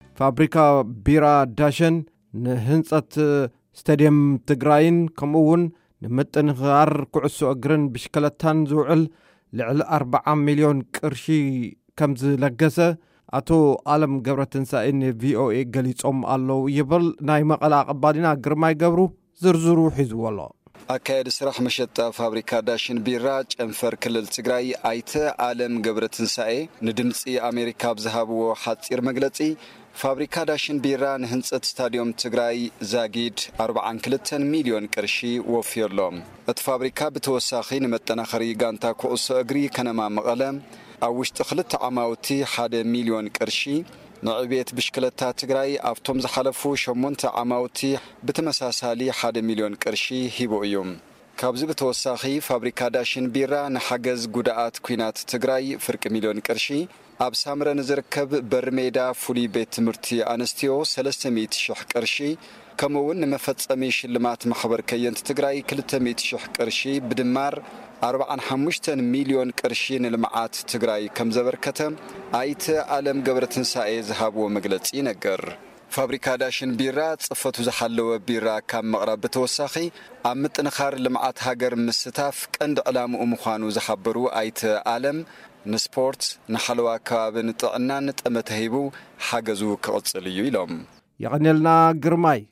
ሪፖርት ደገፍ ዳሽን ቢራ